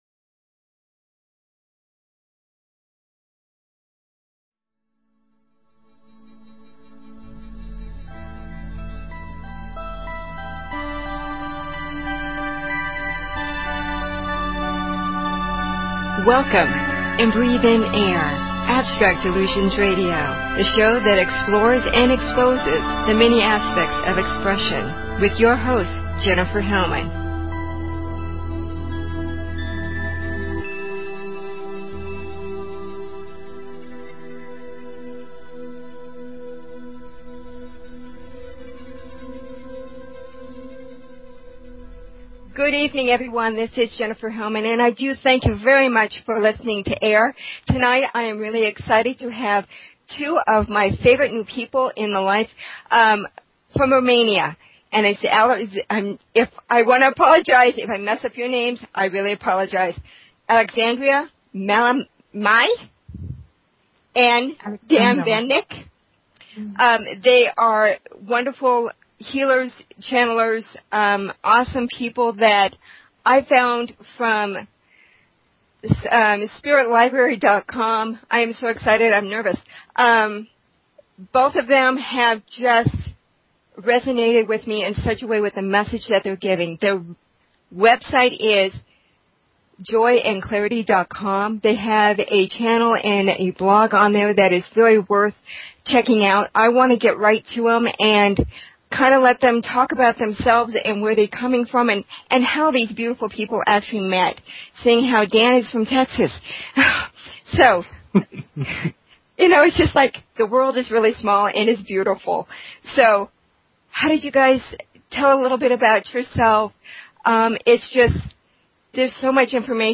Talk Show Episode, Audio Podcast, Abstract_Illusion_Radio and Courtesy of BBS Radio on , show guests , about , categorized as
A channel meditation the last 15 minutes of the program.